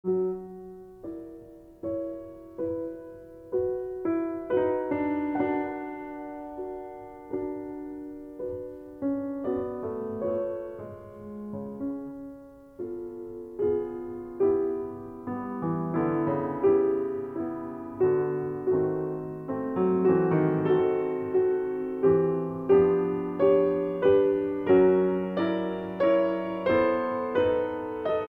鋼琴